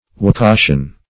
wakashan - definition of wakashan - synonyms, pronunciation, spelling from Free Dictionary